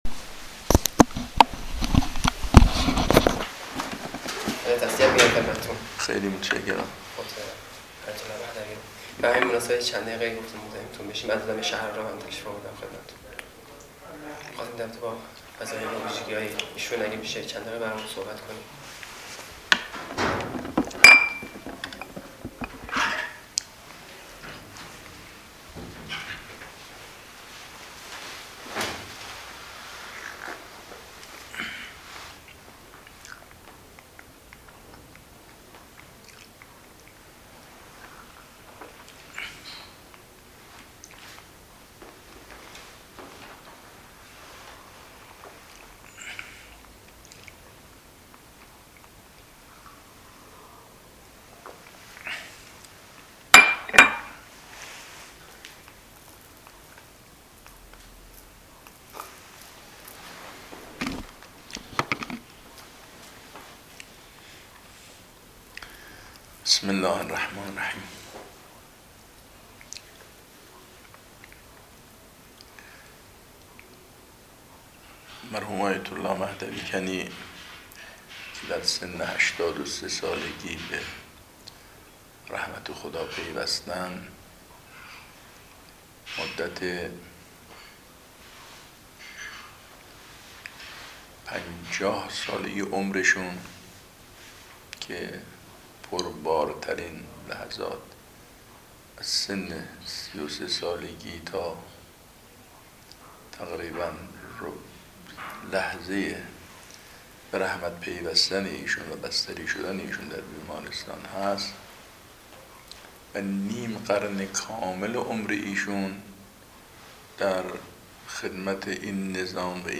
مصاحبه در گذشت مهدوی 930730.MP3